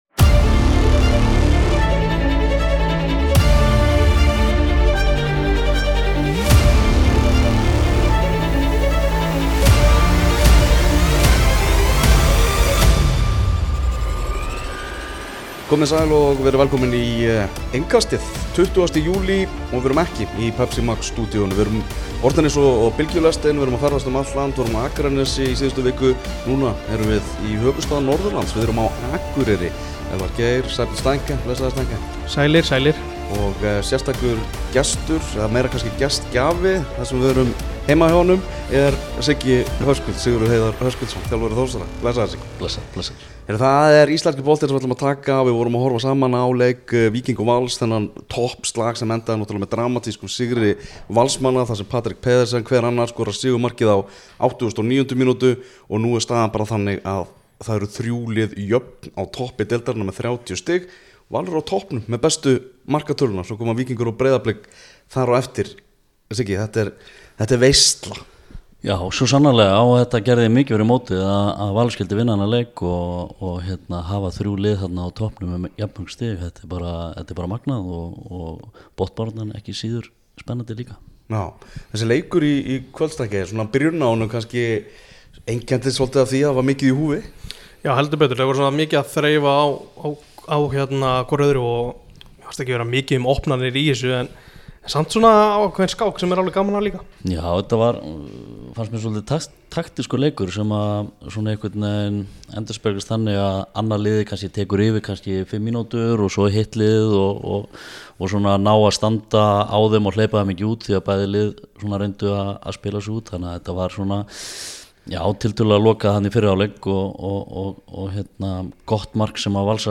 Innkastið heldur áfram að ferðast um landið og er sent út frá Akureyri að þessu sinni!